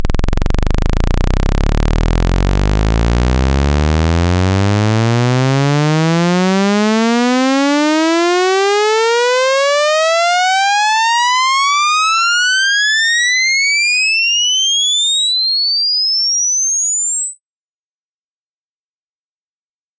To understand the spectrograms, time is left to right—a 20 second sweep from 20 Hz to 20 kHz of a sawtooth. You can see the aliasing as harmonic frequencies bend down at the top, although the algorithm minimizes the aliasing with advantageous choices of switching frequencies at the highest sweep frequencies, where there is the least masking. This uses ten wave tables to sweep the ten octaves of audio from 20 Hz to 20 kHz.
Saw-sweep-one-third-band-old-method-10-tables.wav